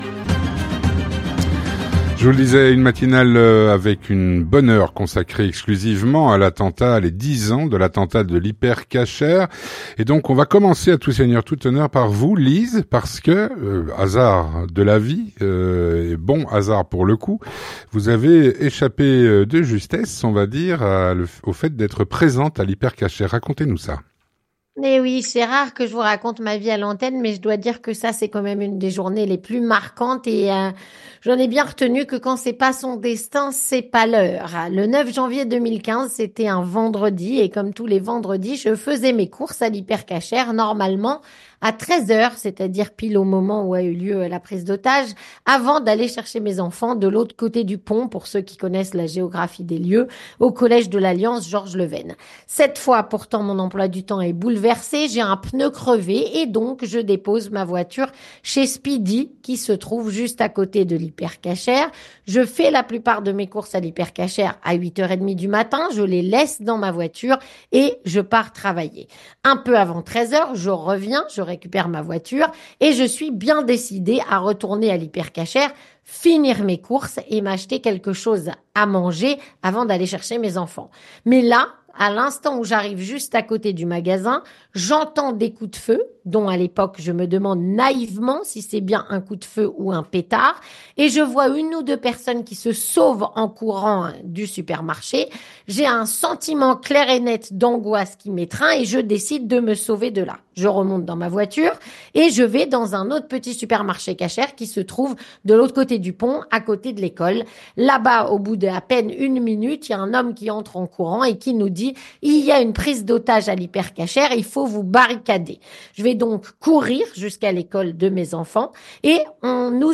3. Témoignage
Elle témoigne.